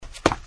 任务：实装和完善部分音效，修复场景问题 1.增加走路时的脚步声音效 2.将Listener转移到玩家身上防止立体声出现问题 3.删除了跑步扬尘效果 4.增加了跑步时的脚步声音效 5.加快了跑步动画为原来的1.2倍，使之步频加快，看起来更加真实。
跑步2.mp3